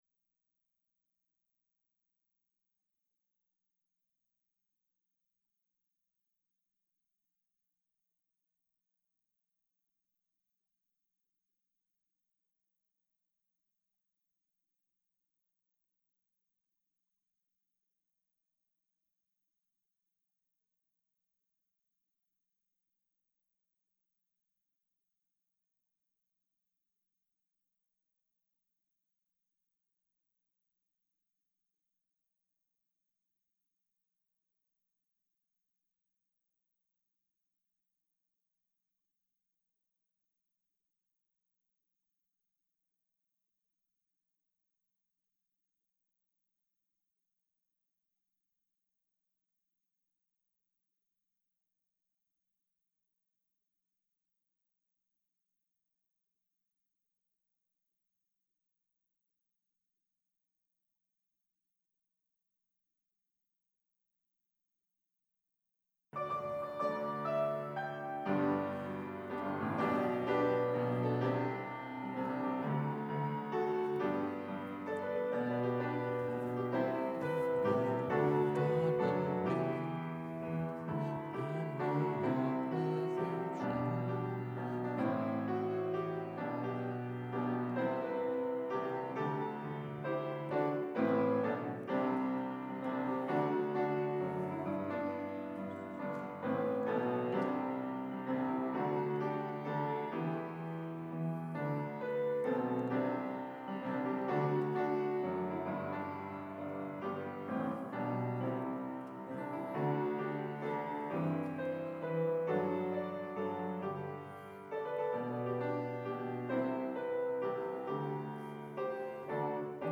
Sermon 2nd Sunday after Epiphany